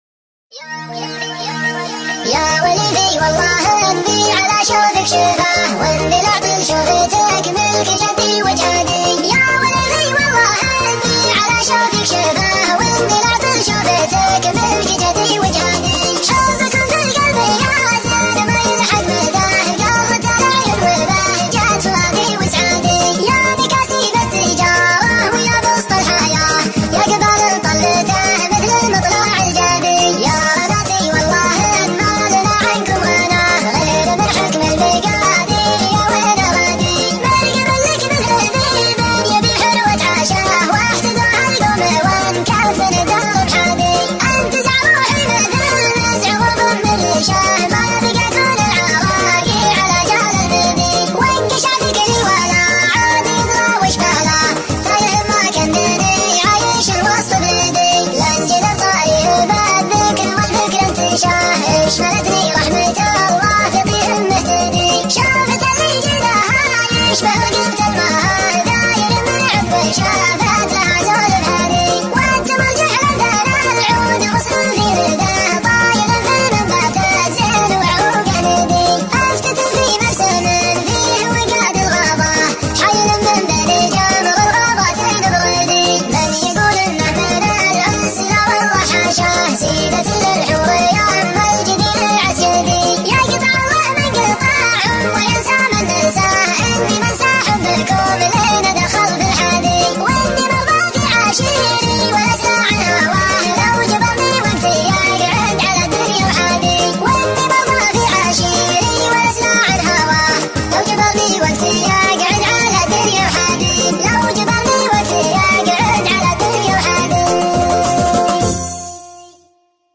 شيلة
مسرعة